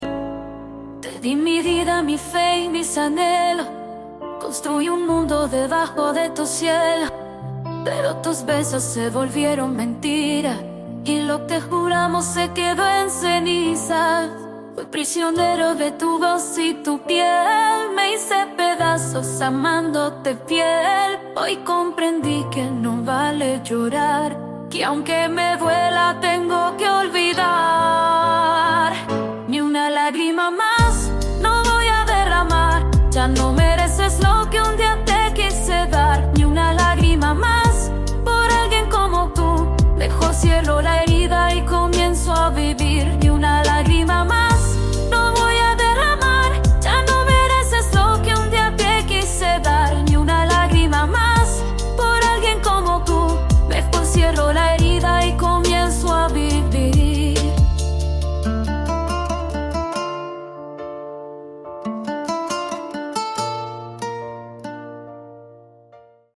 Baladas románticas